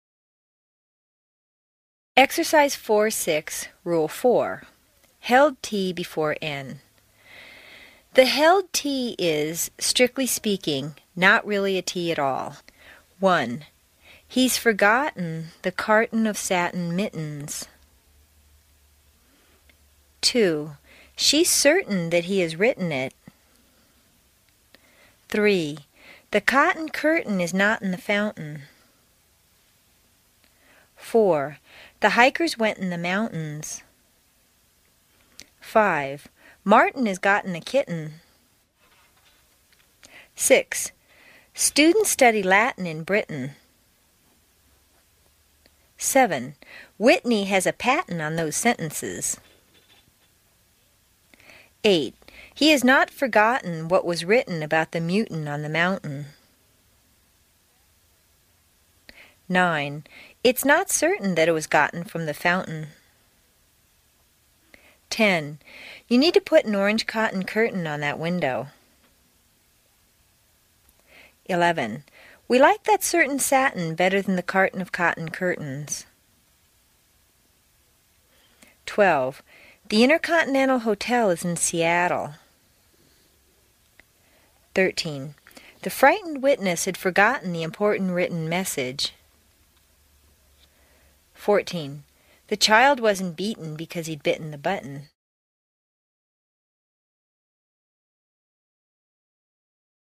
在线英语听力室美式英语正音训练第60期:练习4(6)的听力文件下载,详细解析美式语音语调，讲解美式发音的阶梯性语调训练方法，全方位了解美式发音的技巧与方法，练就一口纯正的美式发音！